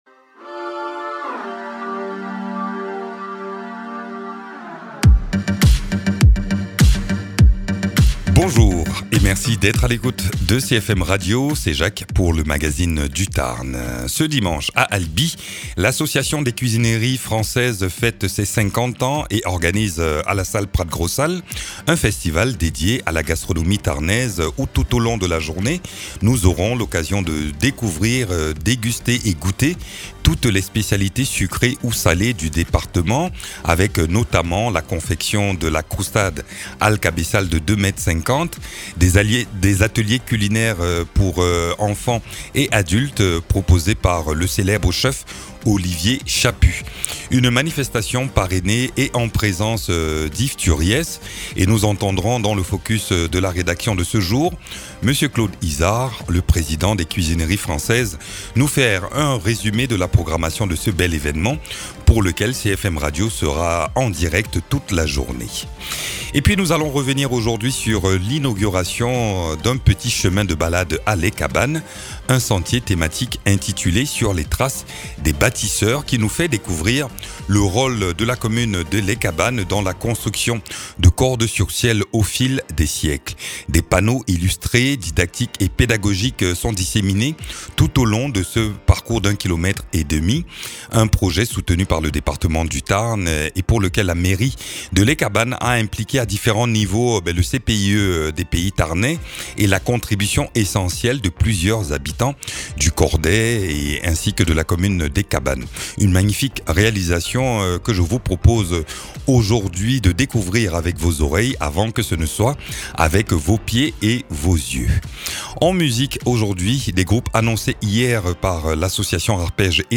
Invité(s) : Patrick Lavagne, maire de Les Cabannes